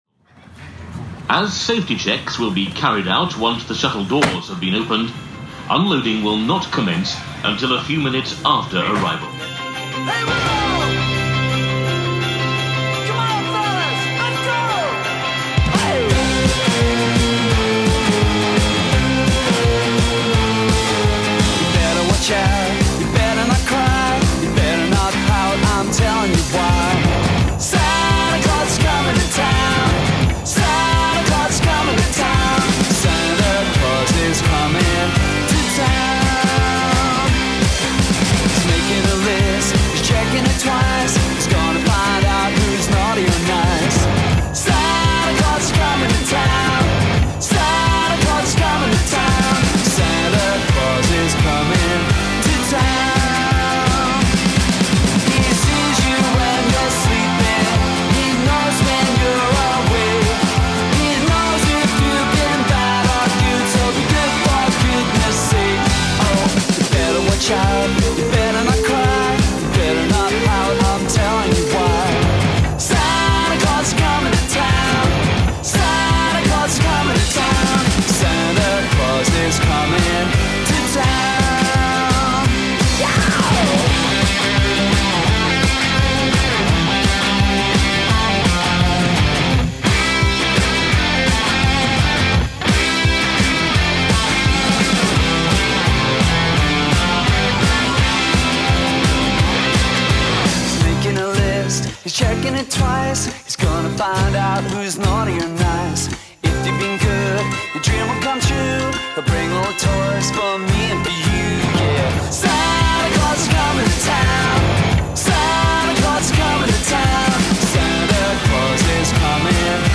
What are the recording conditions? Expect frites (heavy on the mayo), lovely Belgian beers, and football of varying quality: Valenciennes v Flery 91, plus Racing Mechelen v Racing Hades. A couple of links are a bit worse for wear. Then we pack in some of our favourite Christmasy tunes.